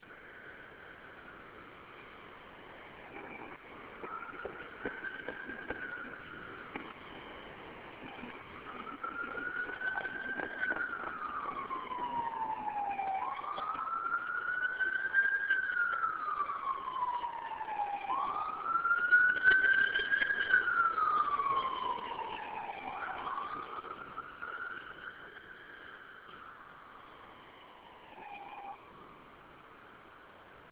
Siren – Hofstra Drama 20 – Sound for the Theatre
I am sitting outside of Memorial Hall, on 4/11/13 at about 5:30. You hear the sounds of footsteps and a siren as a ambulance passes by
field-recording-8.mp3